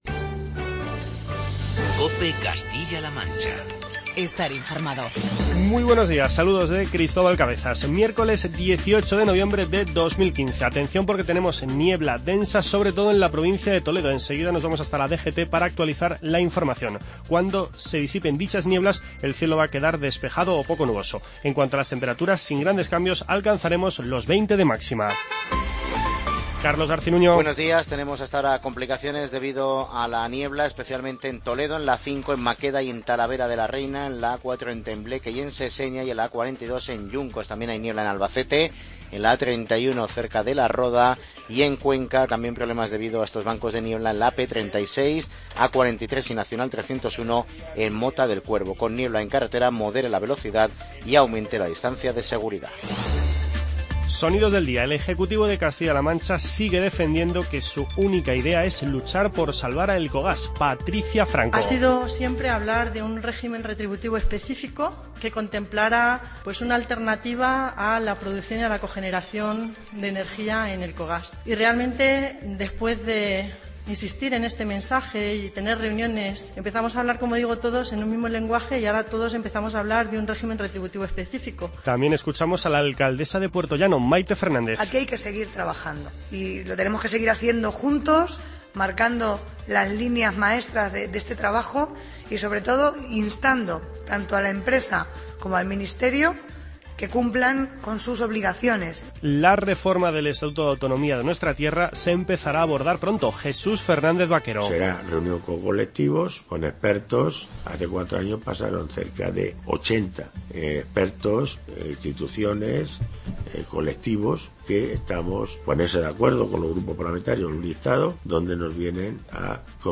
Informativo regional y provincial